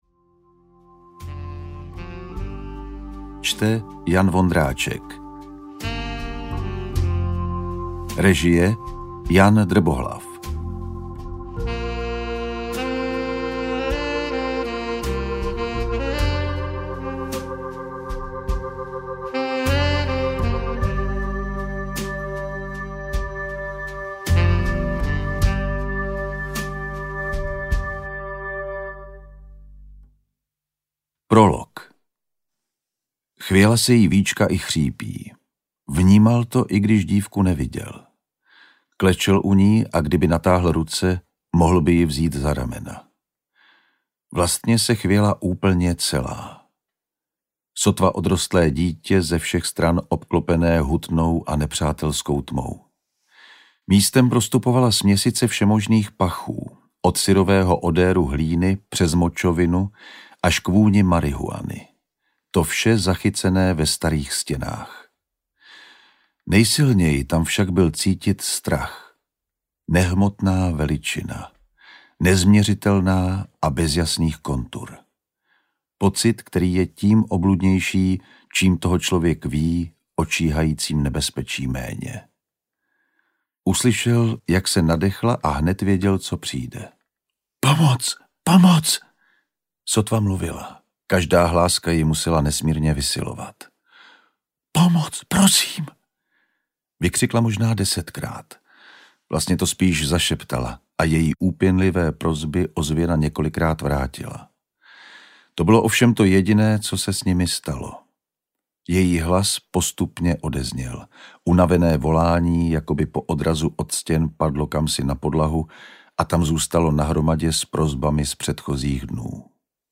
Ve výslužbě audiokniha
Ukázka z knihy
• InterpretJan Vondráček